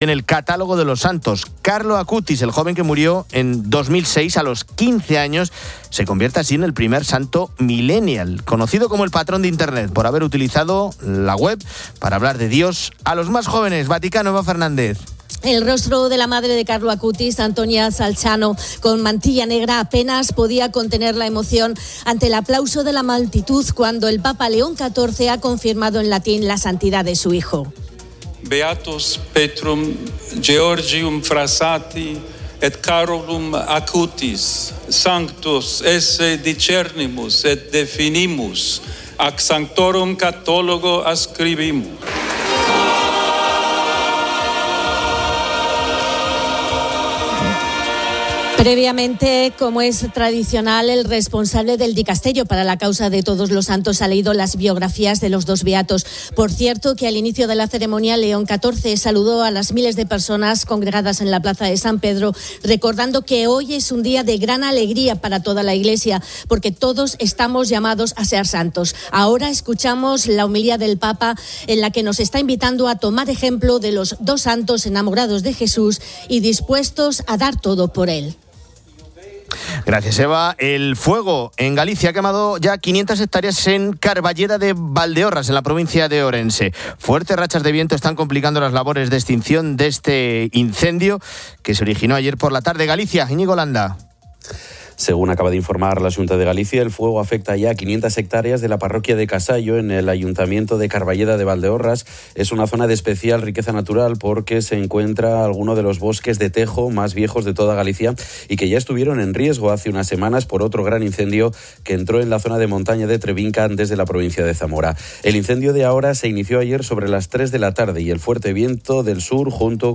Fin de Semana 11:00H | 07 SEP 2025 | Fin de Semana Una entrevista con la cineasta Carla Simón aborda sus películas, la historia de sus padres y el SIDA, y su visión del cine.